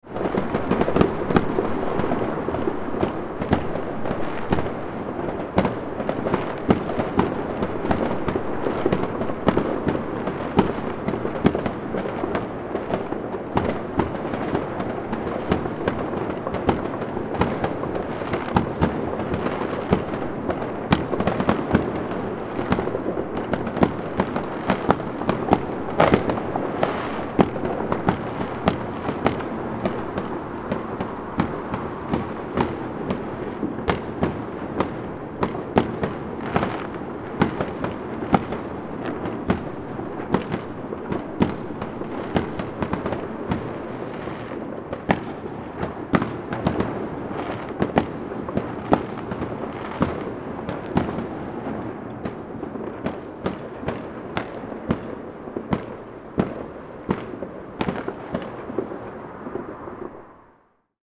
Continuous Fireworks Sound Effect
Description: Continuous fireworks sound effect – midnight fireworks & firecracker explosions for New Year’s celebration.
Continuous-fireworks-sound-effect.mp3